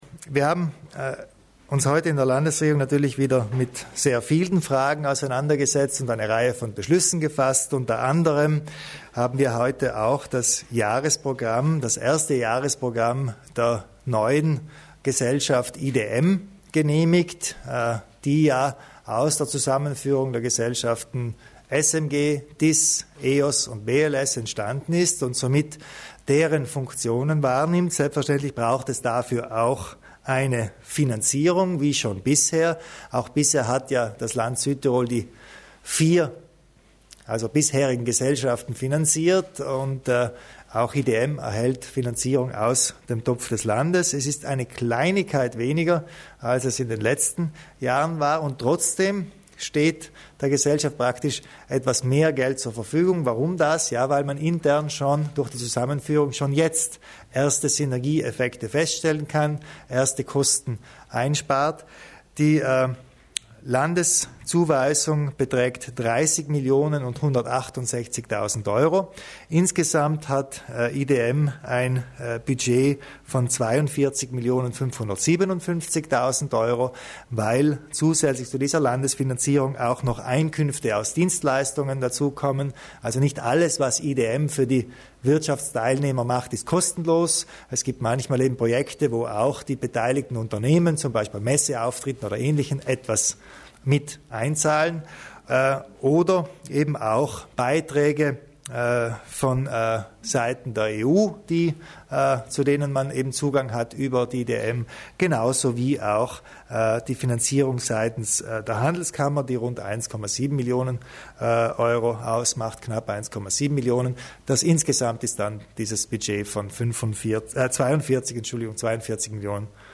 Landeshauptmann Kompatscher zum Tätigkeitsprogramm des neuen Südtirol- Dienstleisters IDM